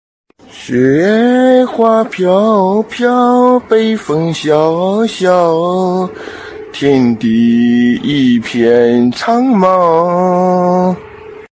levelup.ogg